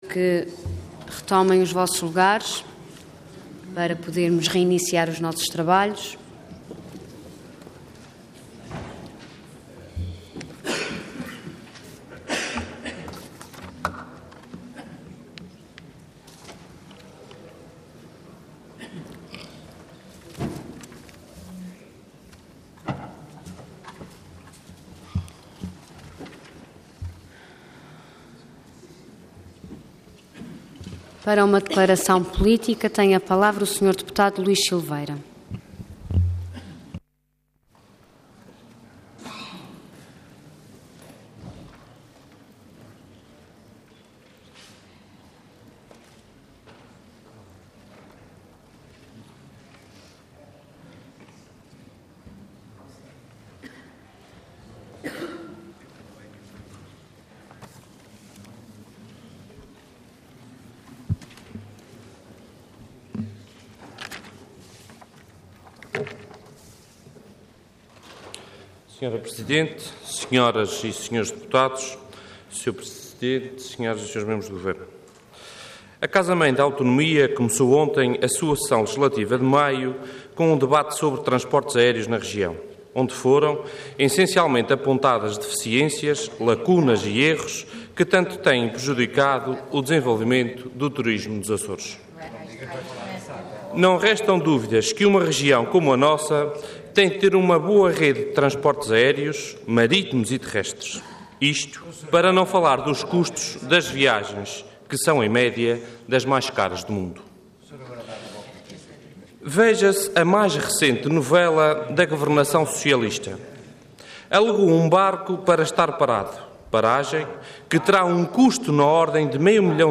Intervenção Declaração Política Orador Luís Silveira Cargo Deputado Entidade CDS-PP